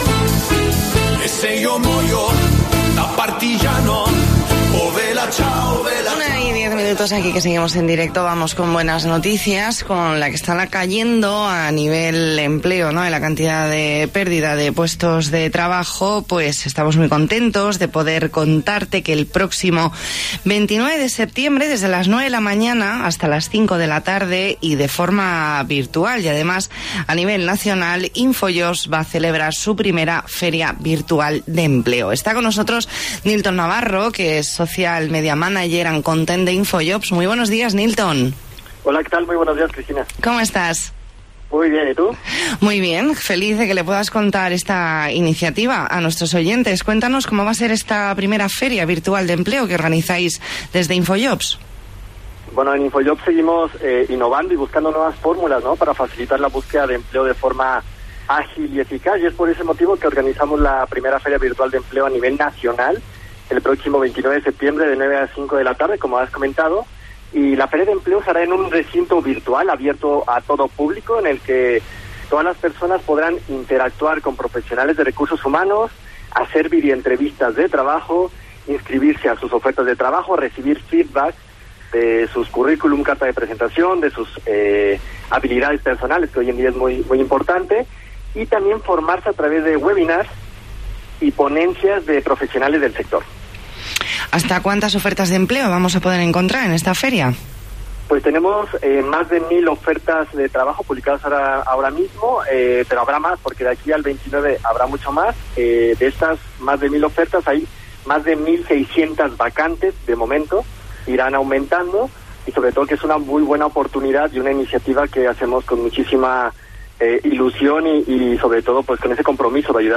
Entrevista en La Mañana en COPE Más Mallorca, viernes 11 de septiembre de 2020.